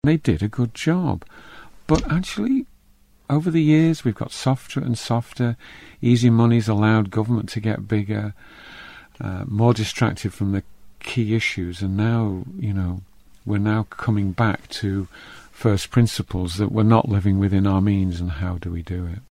Chris Robertshaw appeared on Manx Radio's Agenda programme